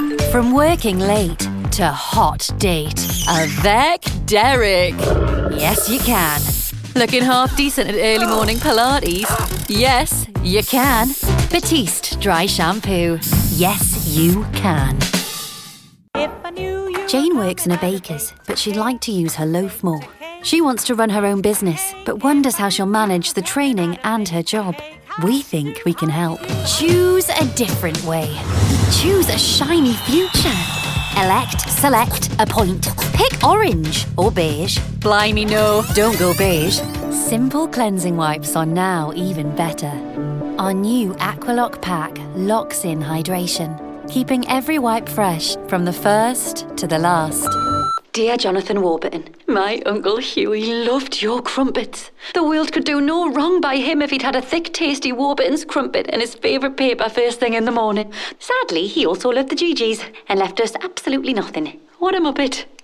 20s-30s. Female. North-East. Newcastle.